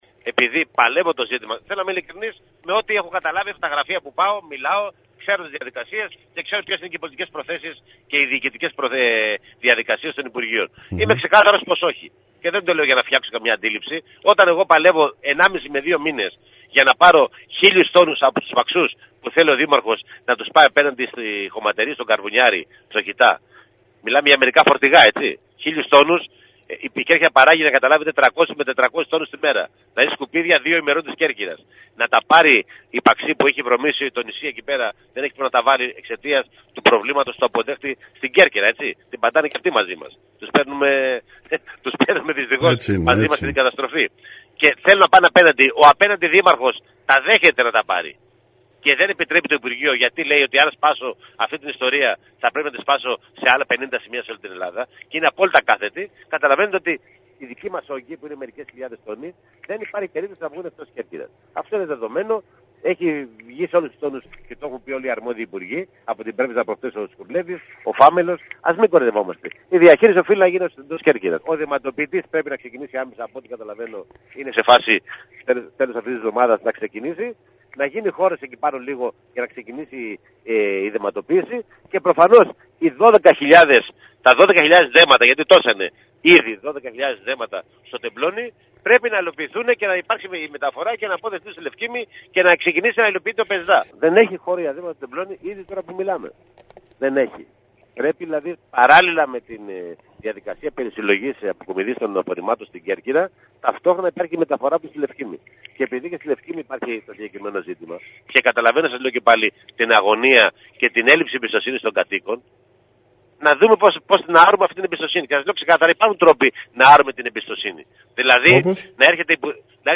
Αναφορικά με το εκρηκτικό θέμα της διαχείρησης των απορριμμάτων στην Κέρκυρα ο βουλευτής του ΣΥΡΙΖΑ Κ. Παυλίδης μιλώντας στην ΕΡΤ Κέρκυρας επεσήμανε ότι δεν υπάρχει κανένα ενδεχόμενο να μεταφερθούν εκτός νησιού τα απορρίμματα ενώ επανέλαβε ότι η μόνη λύση είναι η μεταφορά των δεμάτων στη Λευκίμμη, καθώς το Τεμπλόνι έχει ήδη κορεστεί.